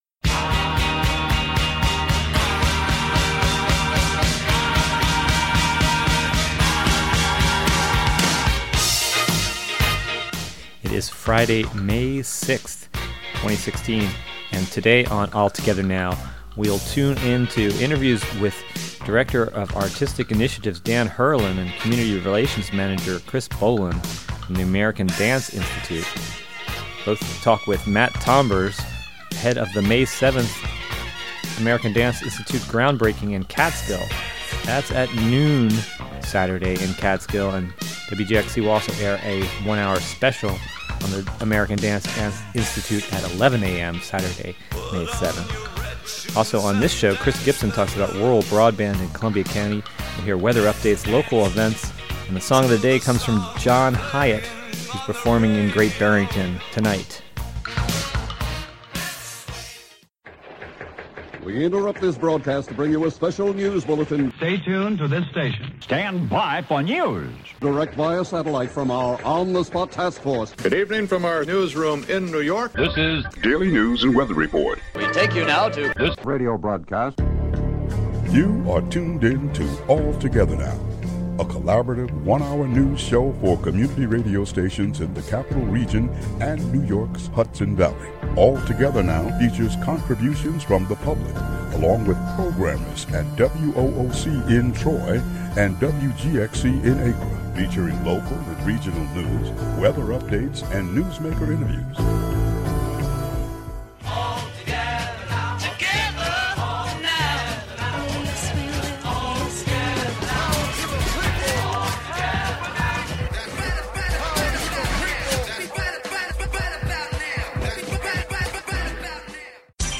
"All Together Now!" is a new evening news show brought to you by WGXC in Greene and Columbia counties, and WOOC in Troy.